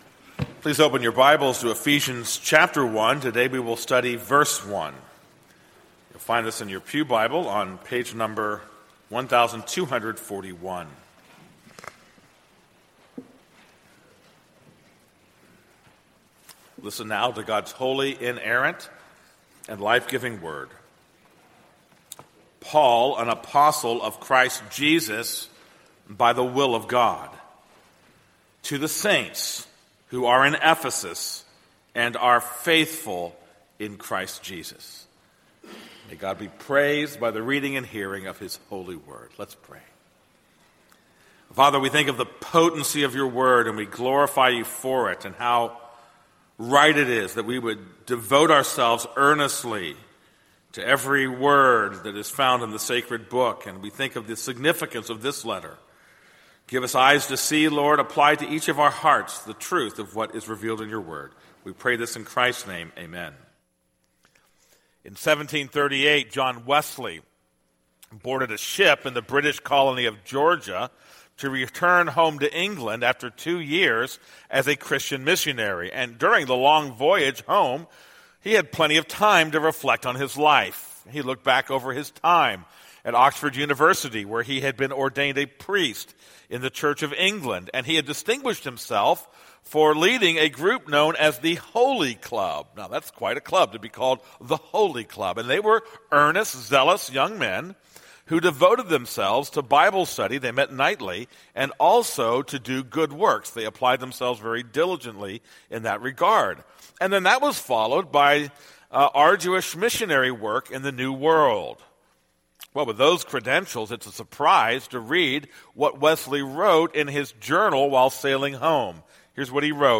This is a sermon on Ephesians 1:1.